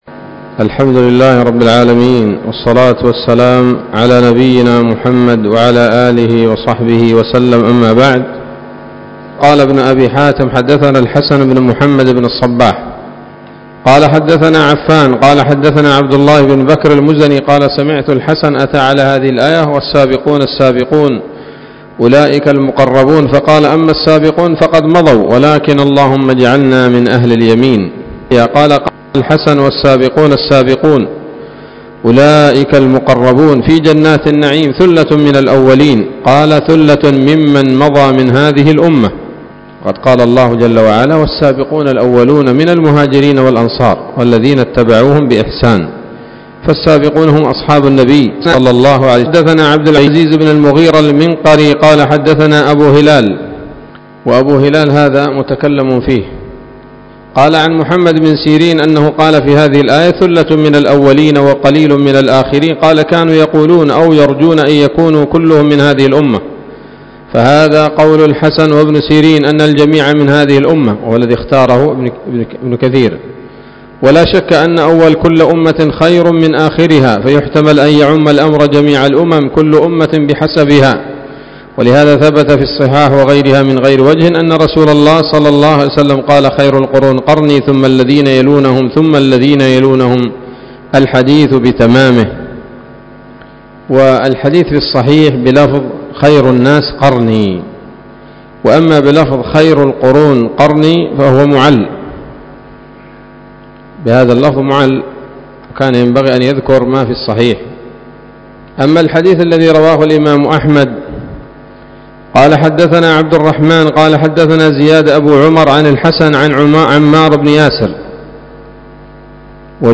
الدرس الرابع من سورة الواقعة من تفسير ابن كثير رحمه الله تعالى